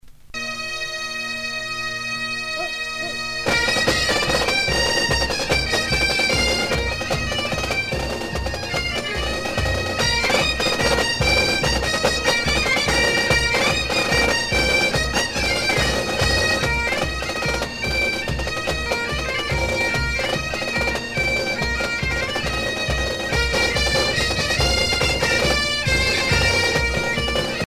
danse : jabadao ;
Pièce musicale éditée